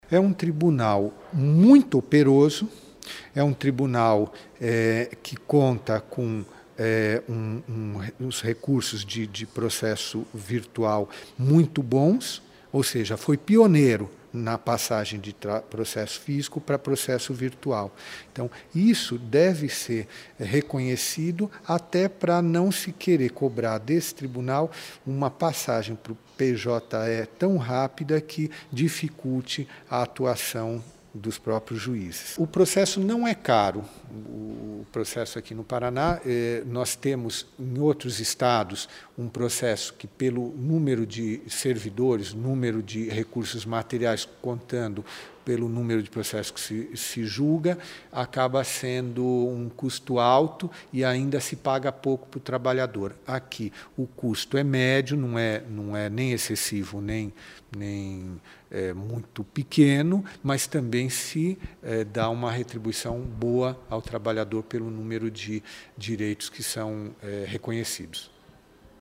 Após divulgar o relatório, o ministro Ives Gandra avaliou o resultado da inspeção em entrevista coletiva.